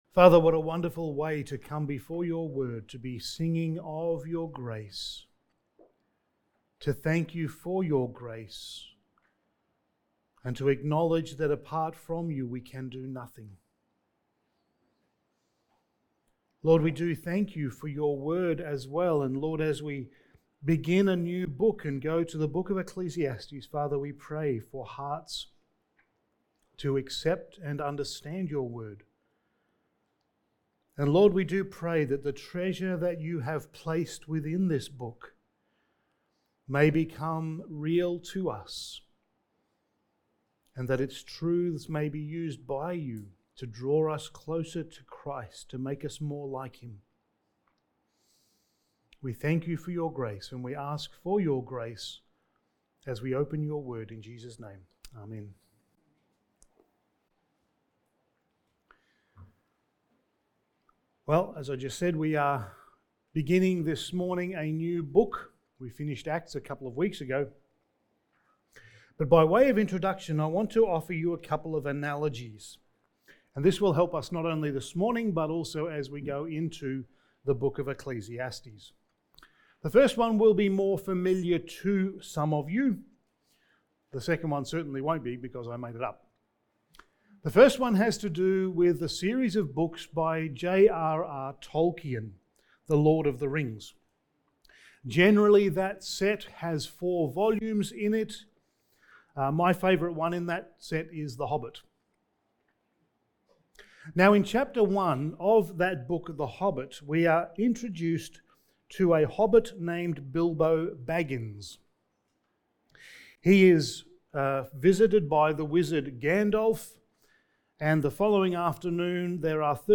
Passage: Ecclesiastes 1:1-12 Service Type: Sunday Morning